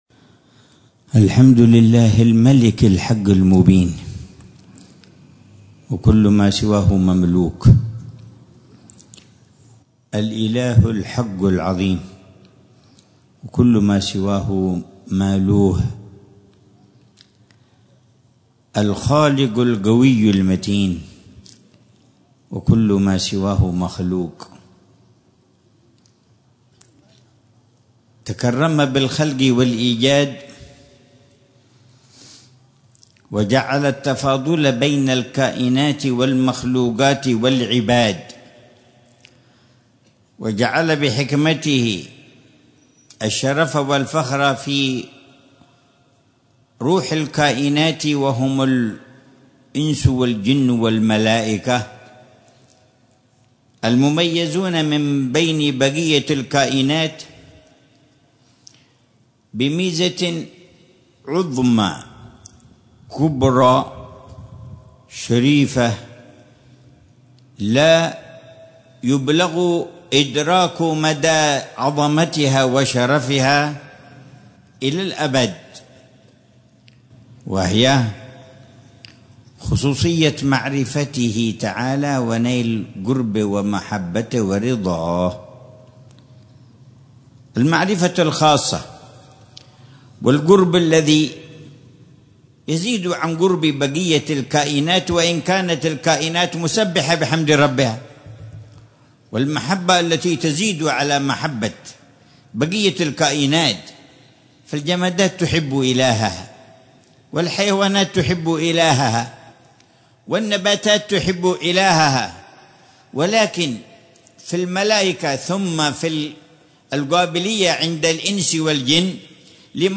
محاضرة العلامة الحبيب عمر بن حفيظ في الحفل الختامي والتكريمي لحلقات المساجد ودور الدعوة بمدينة تريم، ليلة السبت 4 ذي الحجة 1446هـ بعنوان: